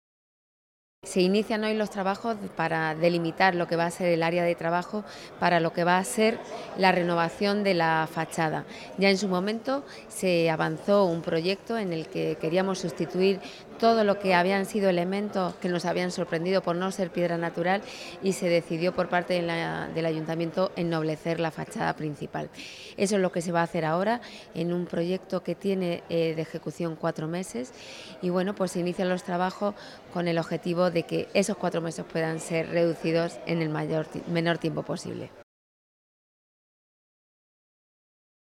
Declaraciones: